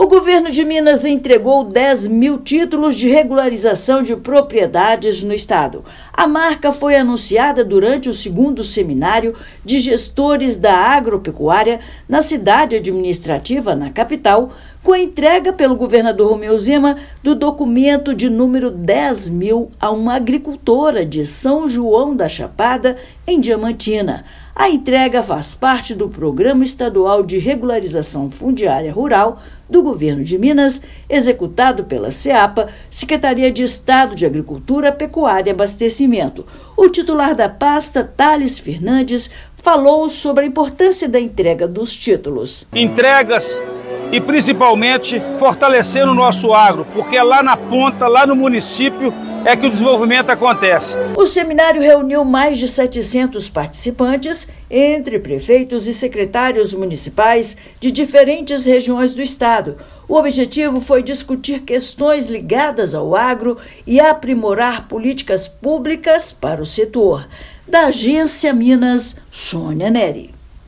Documento garante uma série de benefícios aos produtores rurais; entrega ocorreu durante o 2º Seminário de Gestores da Agropecuária, em Belo Horizonte. Ouça matéria de rádio.